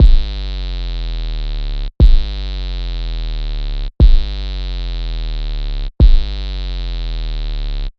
Trap Sub Bass 001 120BPM
描述：Trap Sub Bass Loops。
Tag: 120 bpm Trap Loops Bass Synth Loops 1.35 MB wav Key : Unknown Mixcraft